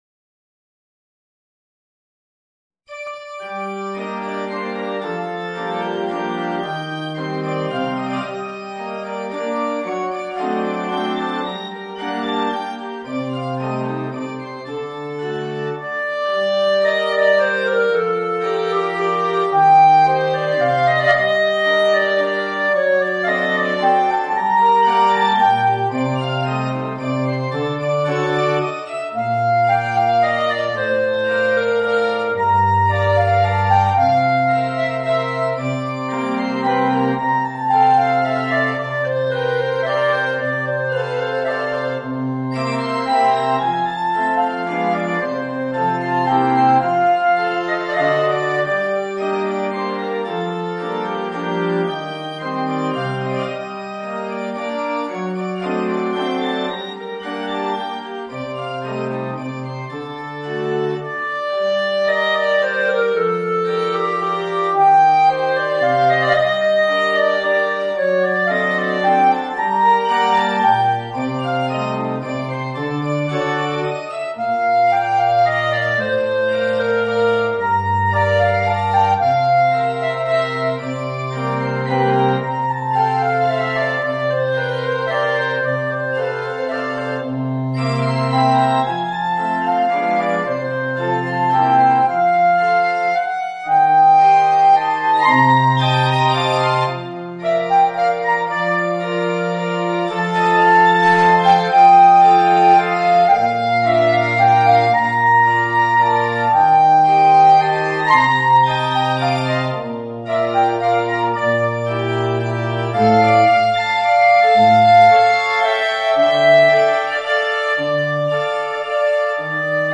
Voicing: Clarinet and Organ